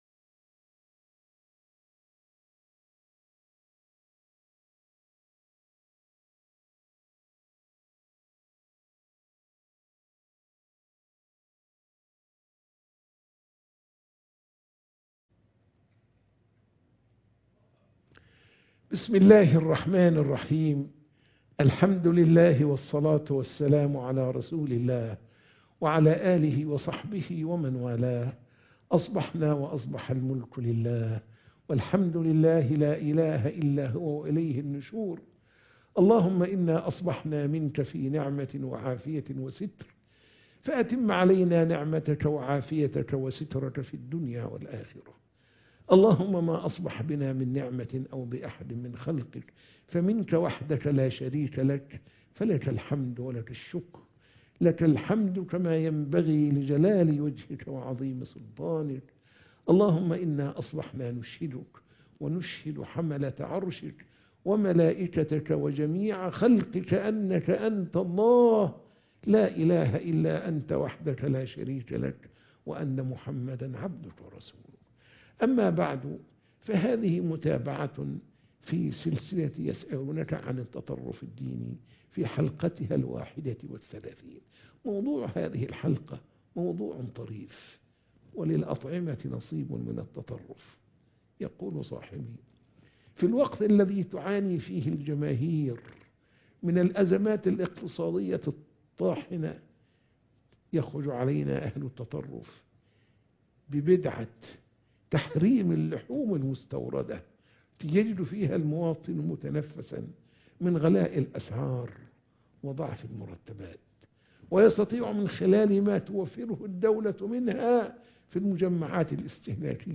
درس الفجر - يسألونك عن التطرف الديني